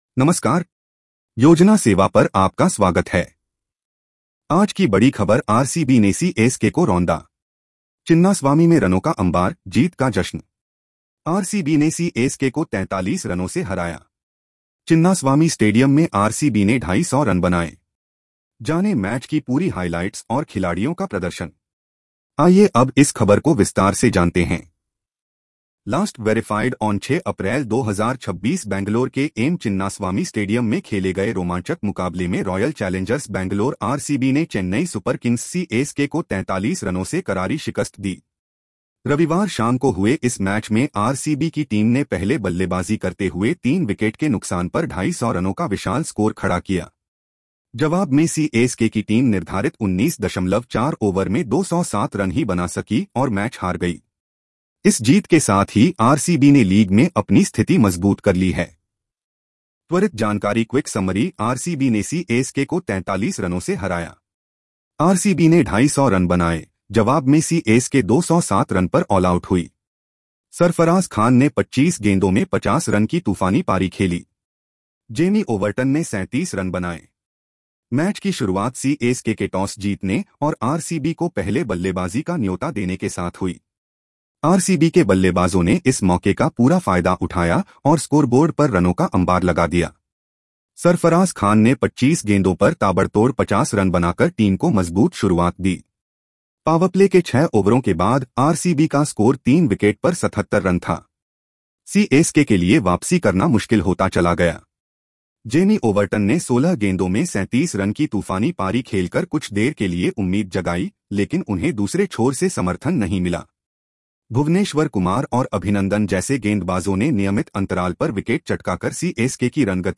🎧 इस खबर को सुनें (AI Audio):